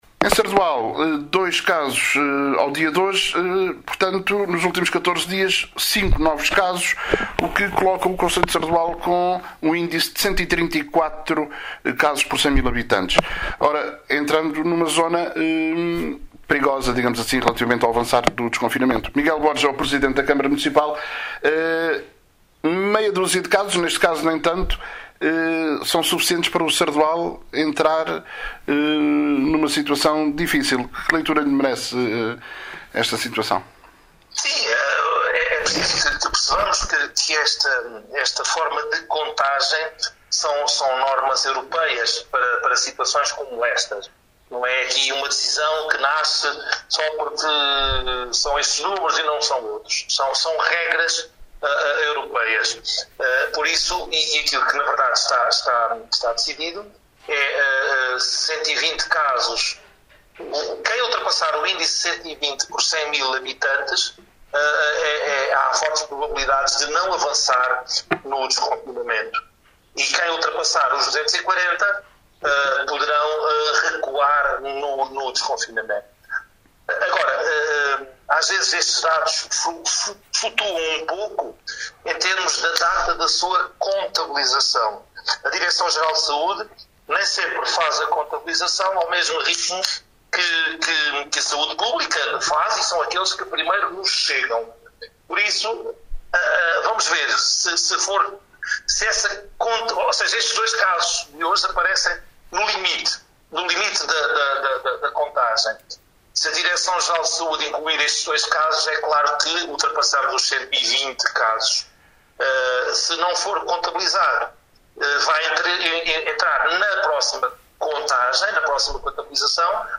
ÁUDIO: MIGUEL BORGES, PRESIDENTE CM SARDOAL: